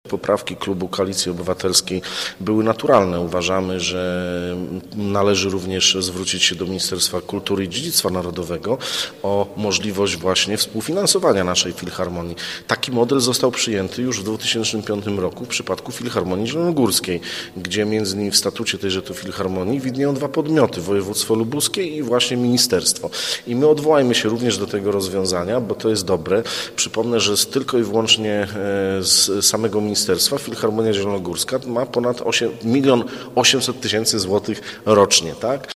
Chodziło o to by do filharmonii dołożyło także ministerstwo. Mówi szef klubu radnych KO Radosław Wróblewski: